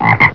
snd_32020_Frog.wav